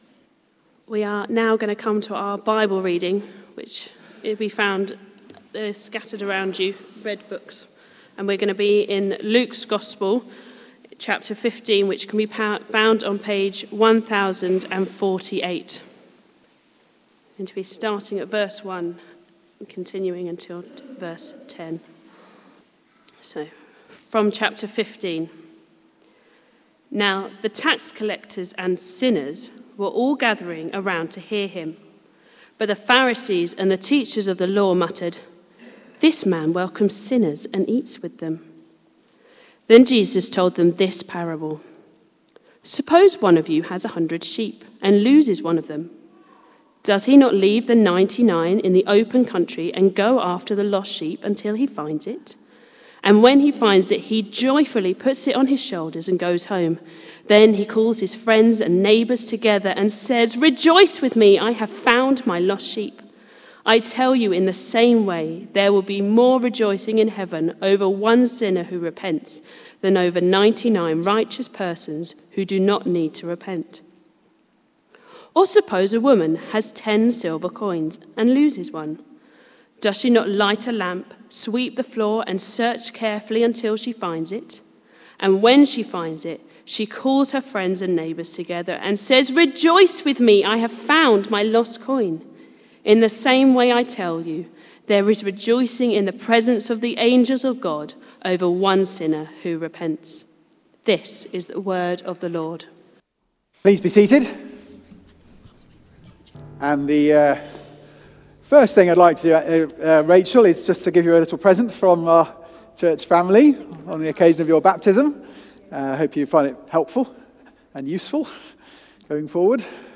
Theme: Baptism: Rejoicing in Redemption Sermon